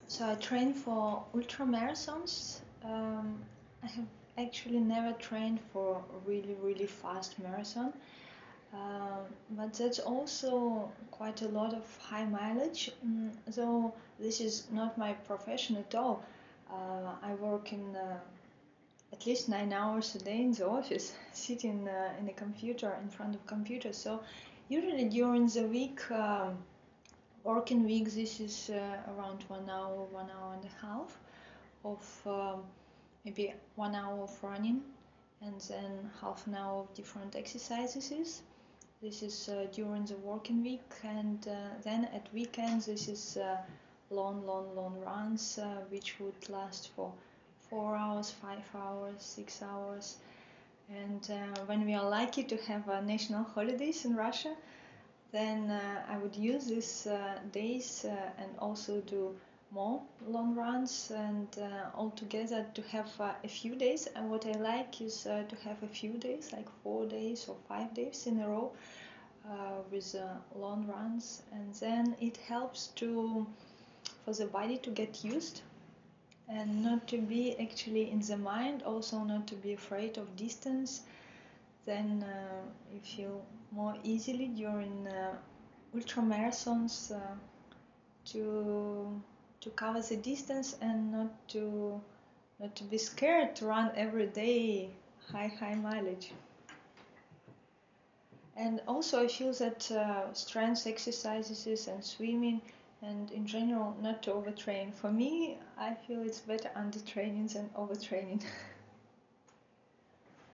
Interview: how meditation brought me to ultramarathon running | Radio Sri Chinmoy